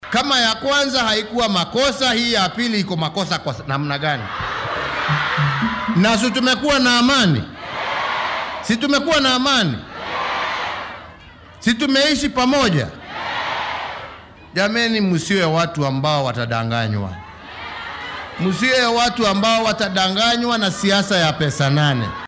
Madaxweynaha dalka Uhuru Kenyatta oo shalay ku sugnaa ismaamulka Nakuru ayaa shacabka halkaasi ku nool iyo si guud kenyaanka ku boorriyay inay si midnimo ah ku wada noolaadaan.